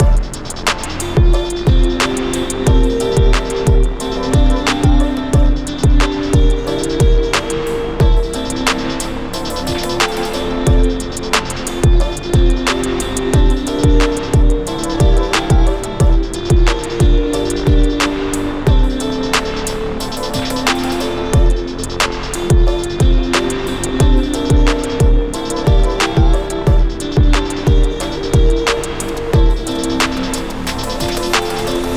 D Minor
Guitar Chime